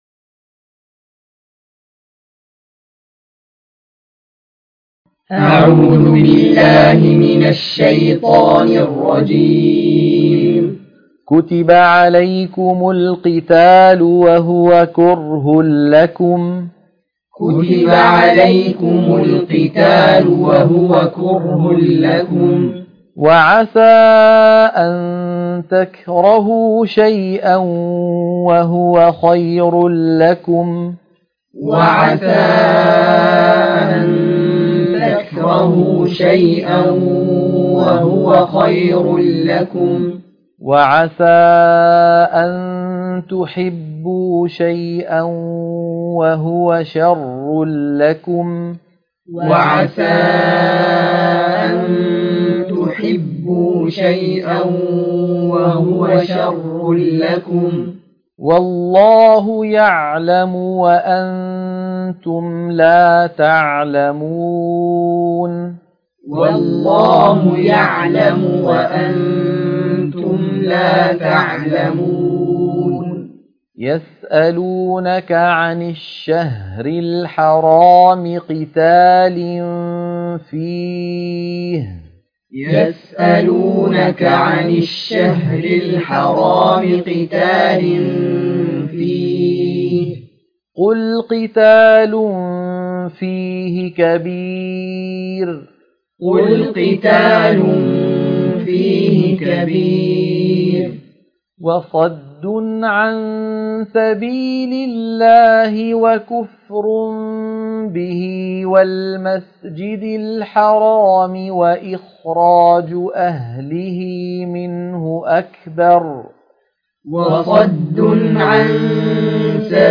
عنوان المادة تلقين سورة البقرة - الصفحة 34 _ التلاوة المنهجية